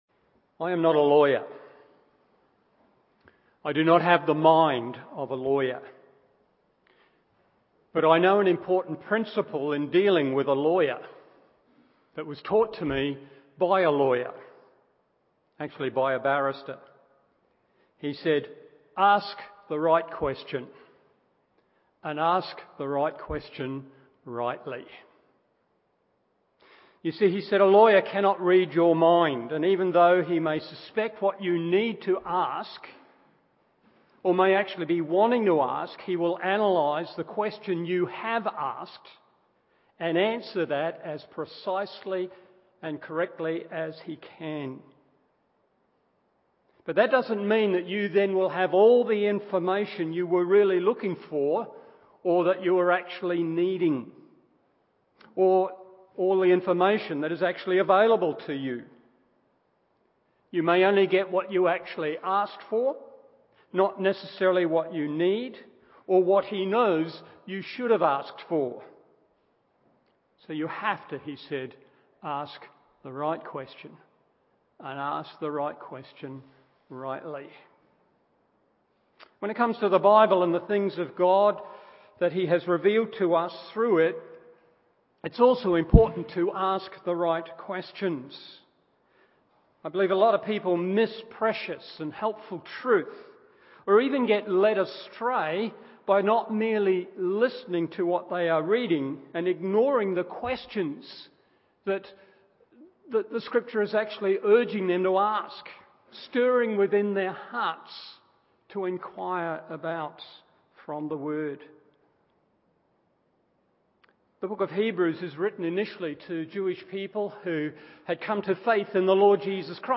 Morning Service Hebrews 1:4 1. The Question of the Ages 2. he Implication for the Ages…